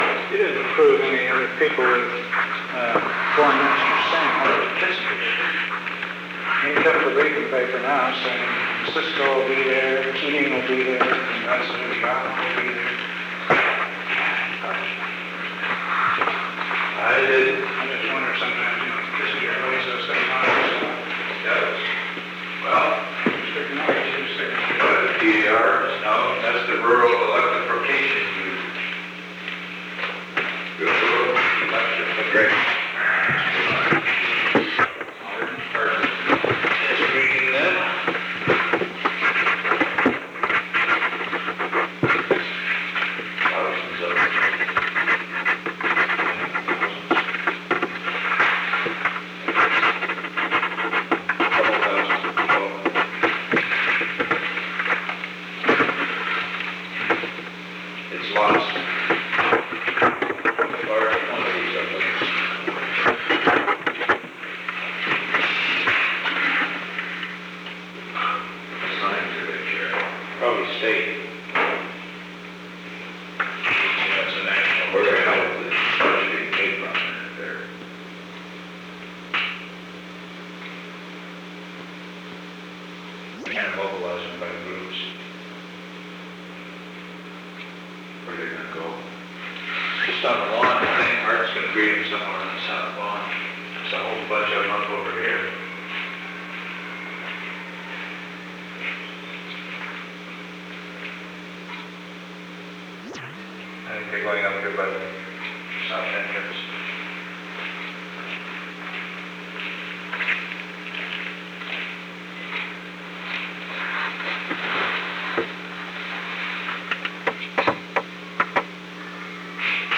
Secret White House Tapes
Conversation No. 522-8
Location: Oval Office
The President met with H.R. (“Bob”) Haldeman and Alexander P. Butterfield.